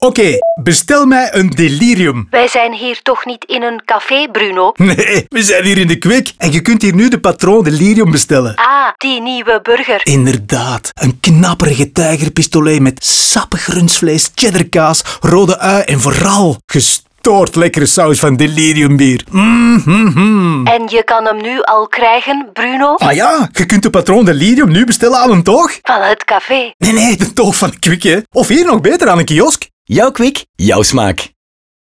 Naast het concept van de burger, bedacht Happiness een TV-billboard, een nieuwe audiospot in de restaurants, (D)OOH in 2, 8, 10 en 20 m2, POS-materiaal en een socialmediacampagne.